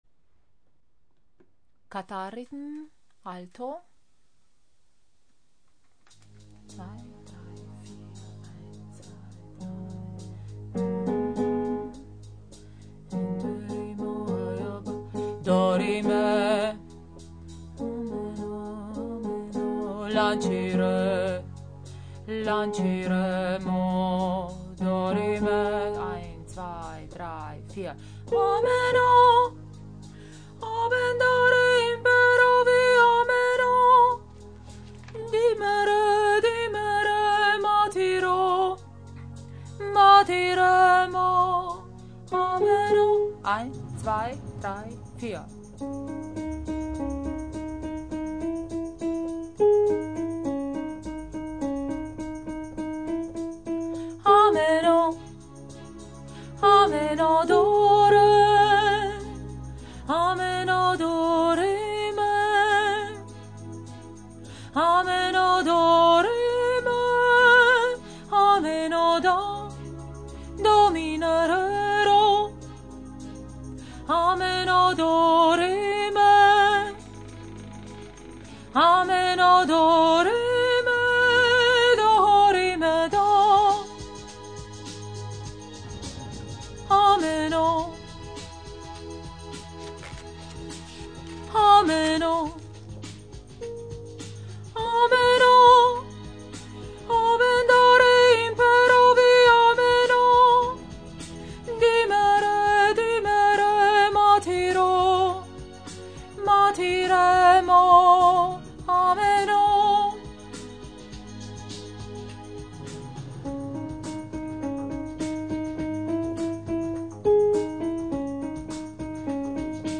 Ameno – Alto